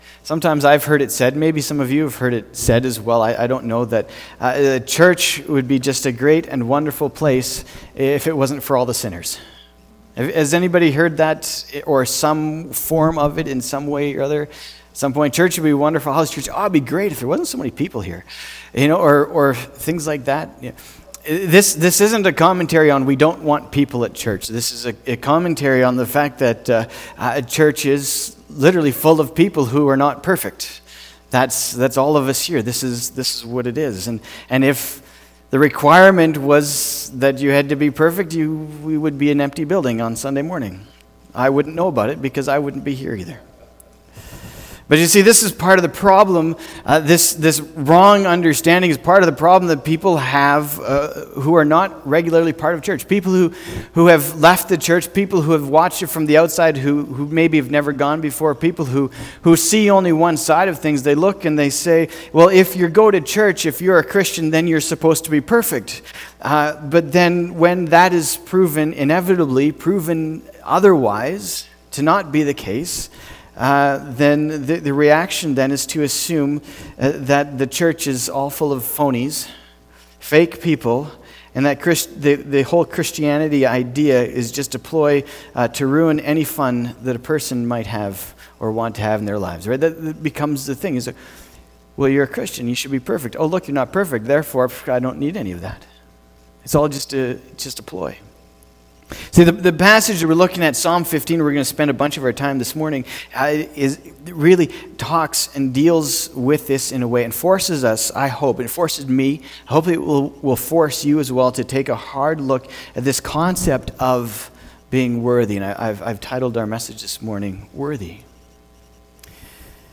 february-2-2020-sermon.mp3